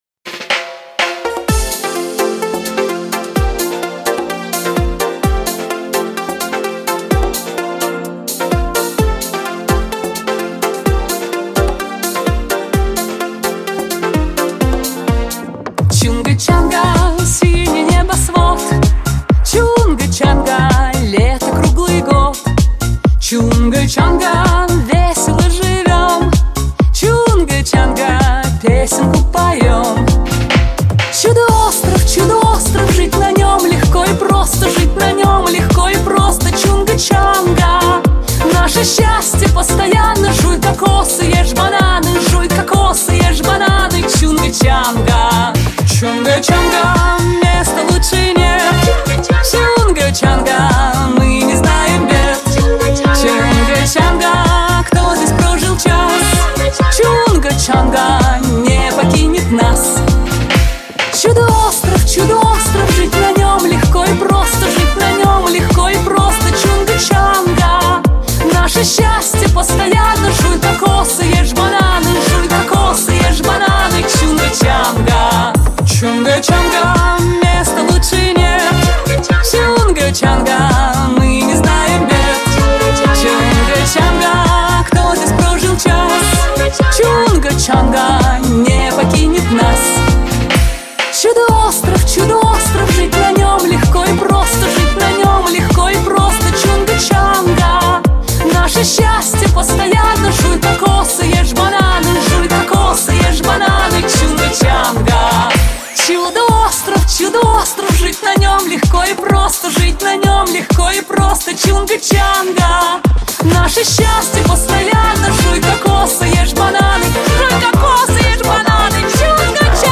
Кавер